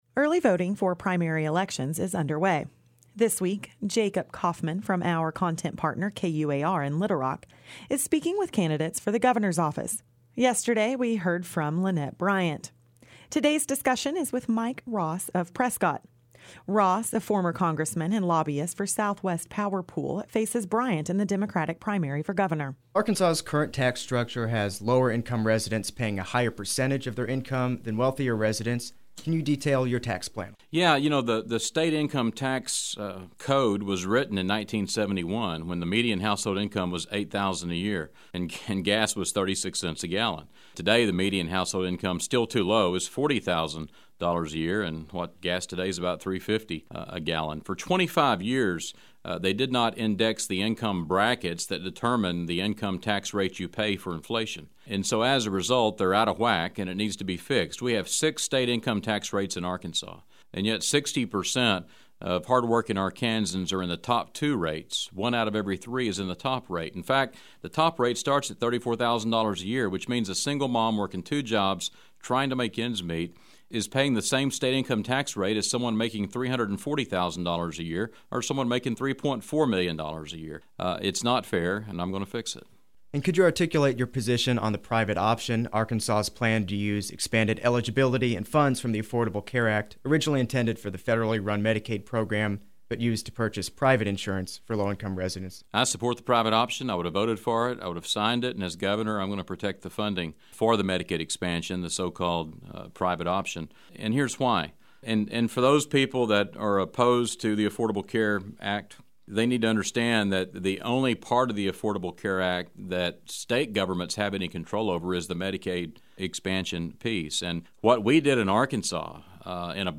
Our content partner KUAR in Little Rock is interviewing Arkansas' gubernatorial candidates. Today's conversation is with Mike Ross.